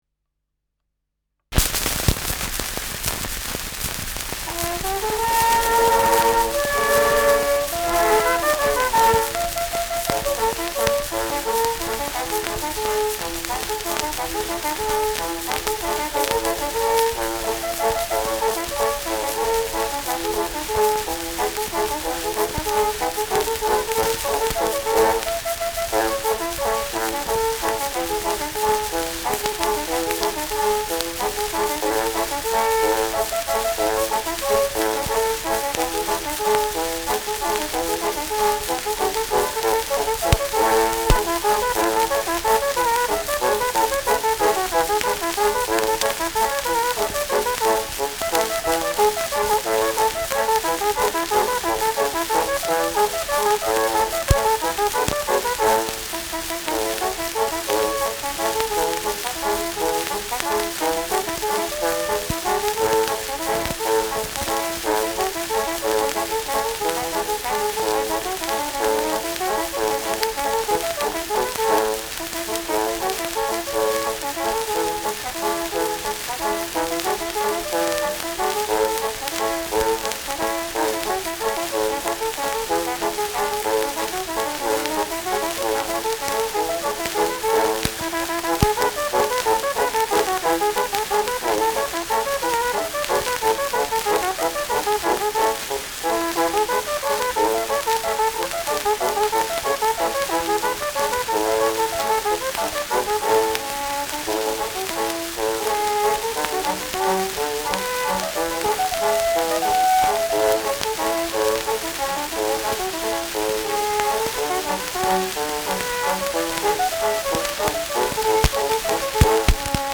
Schellackplatte
starkes Rauschen : Knacken : „Hängen“ bei 2’06’’
Dachauer Bauernkapelle (Interpretation)
[München?] (Aufnahmeort)